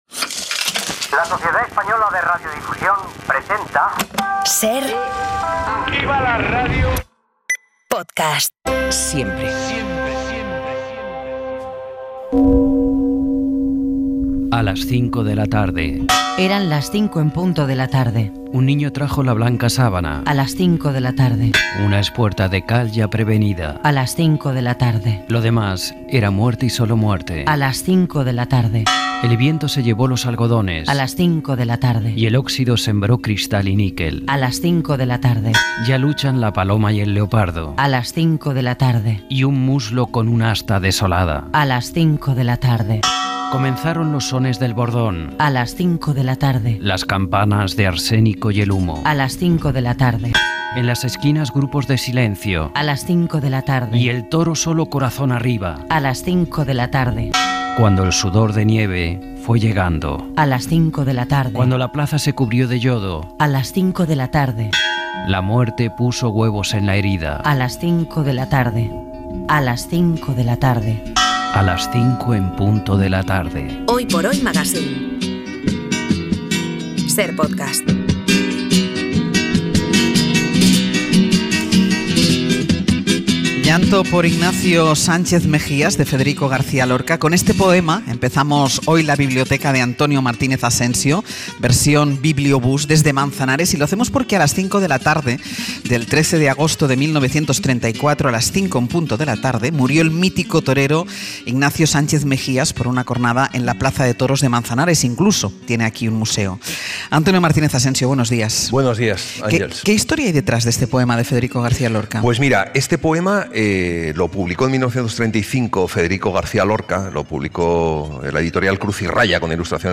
desde Manzanares (Ciudad Real)